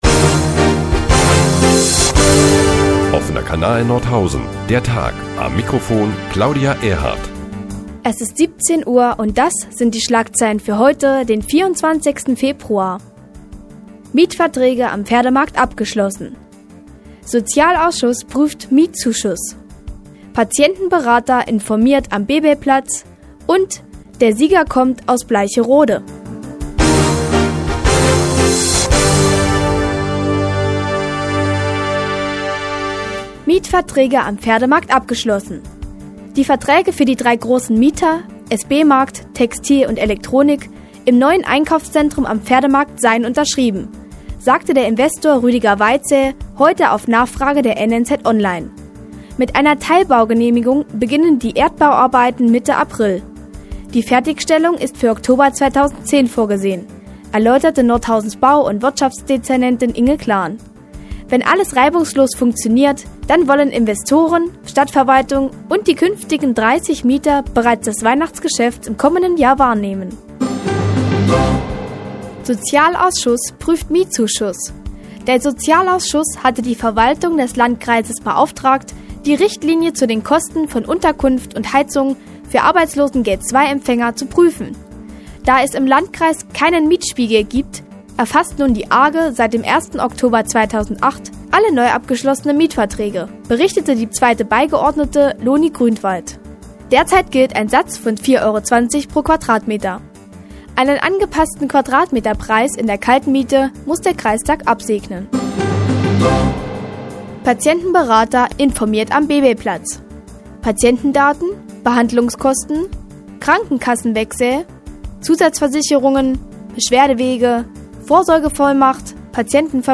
Die tägliche Nachrichtensendung des OKN ist nun auch in der nnz zu hören. Heute unter anderem mit dem Abschluss der Mietverträge am Pferdemarkt und ein Patientenberater informiert am Bebelplatz.